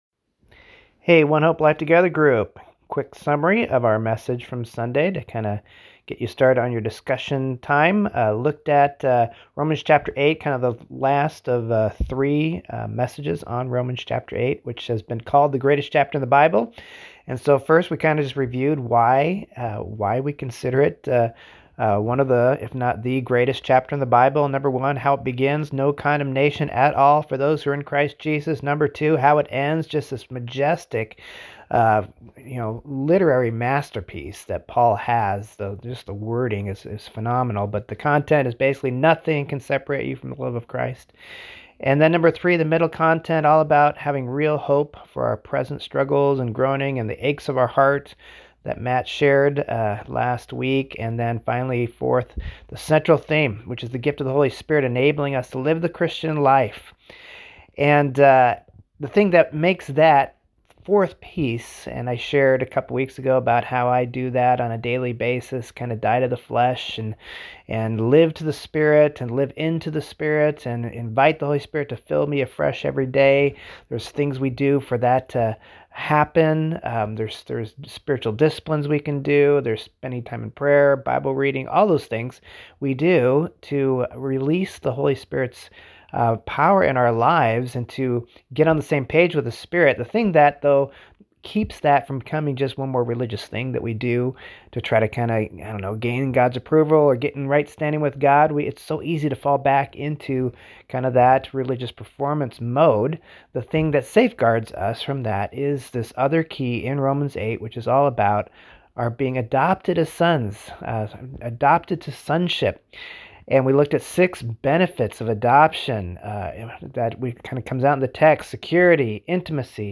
Sermon Recap for Life Together Groups